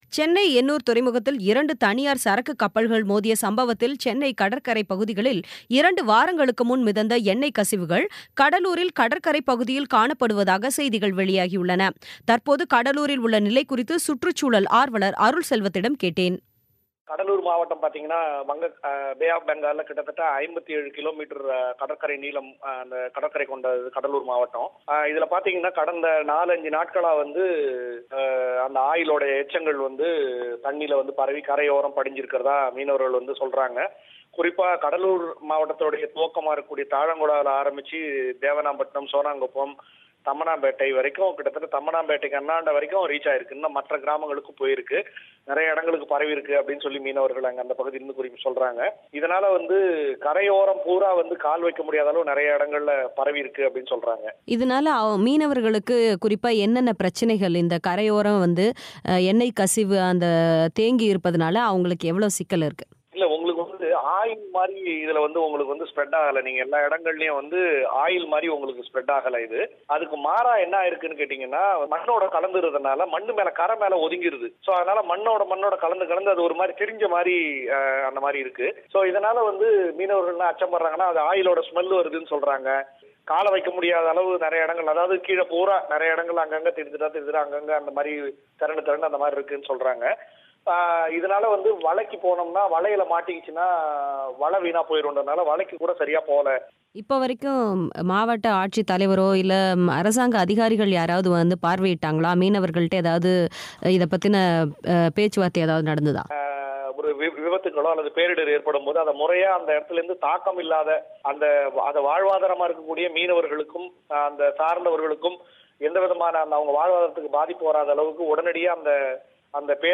பேட்டி.